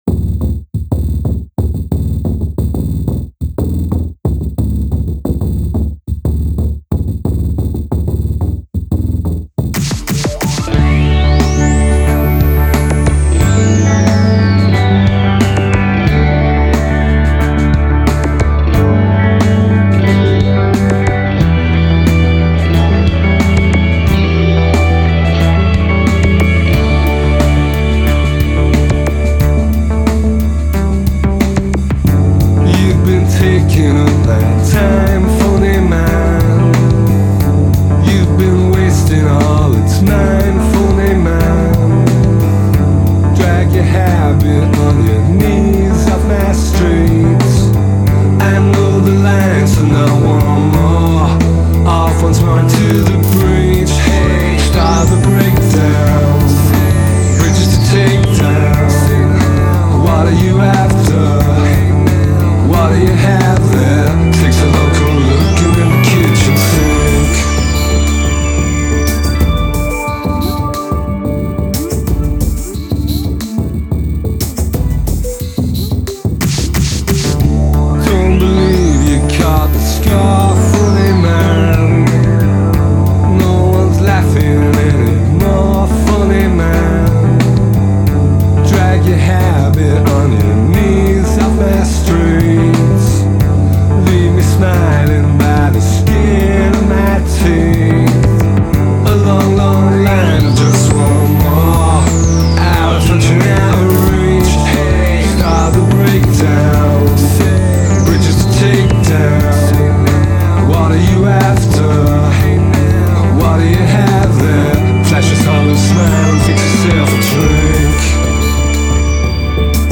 Excursions in Hyper EDM.
guitar
Maybe it’s the blending of EDM with guitar
is a lighter, almost poppy take on things